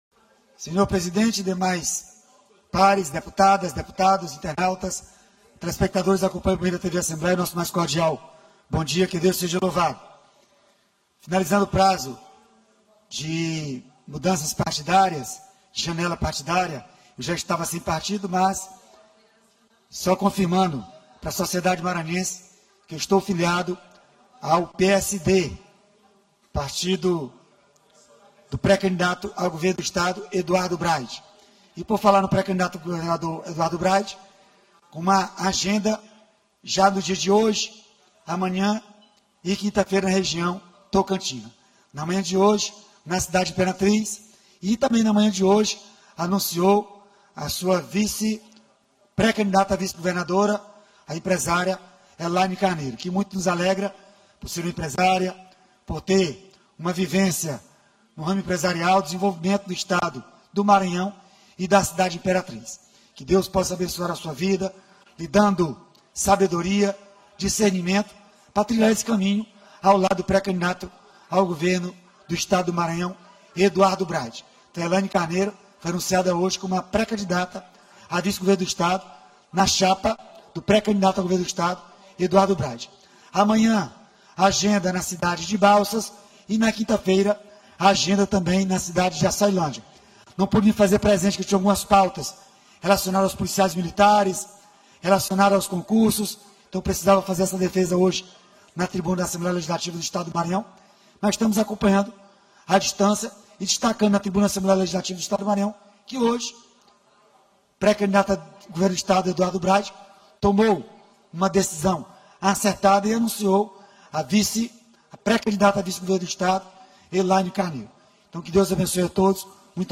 Início -> Discursos